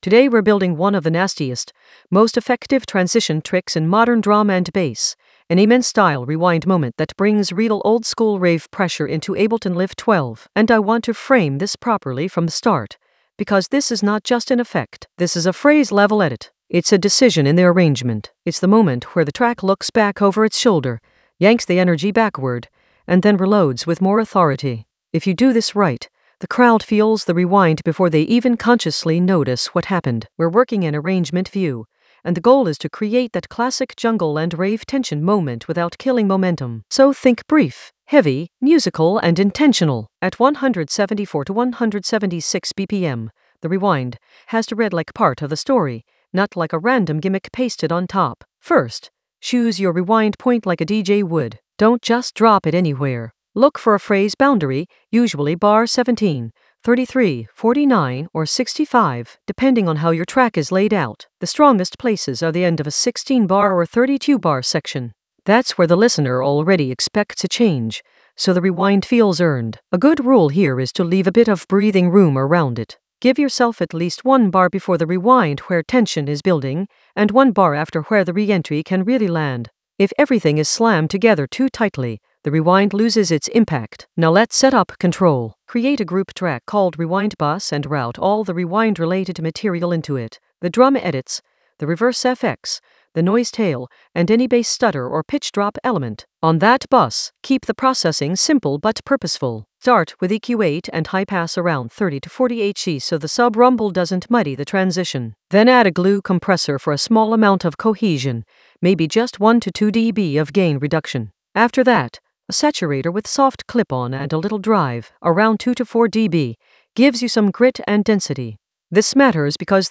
An AI-generated advanced Ableton lesson focused on Color an Amen-style rewind moment for oldskool rave pressure in Ableton Live 12 in the Arrangement area of drum and bass production.
Narrated lesson audio
The voice track includes the tutorial plus extra teacher commentary.